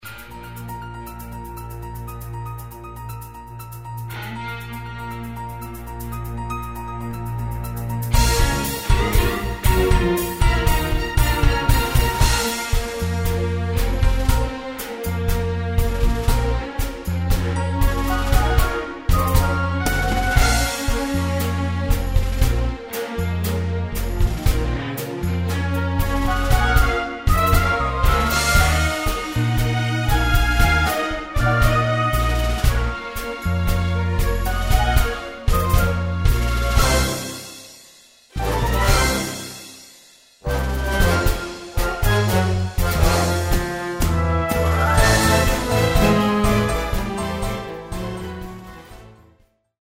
Instrumental
backing track